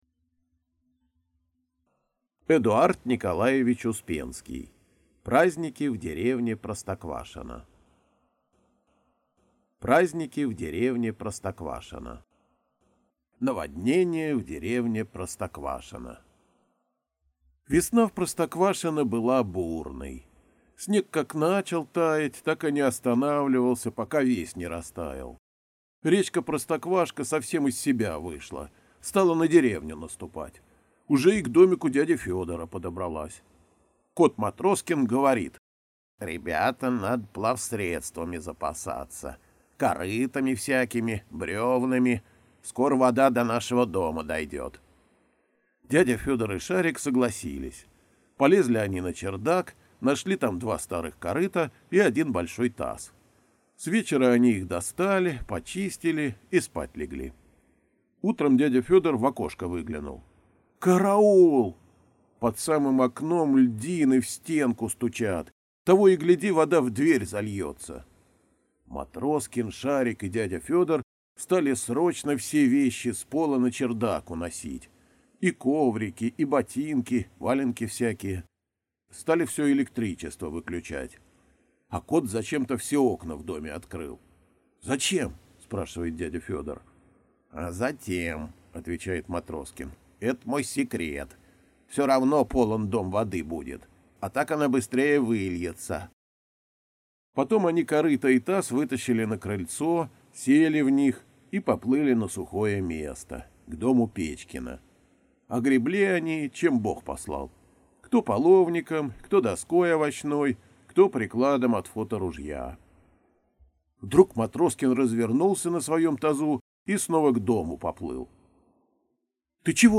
Аудиокнига Праздники в деревне Простоквашино (сборник) | Библиотека аудиокниг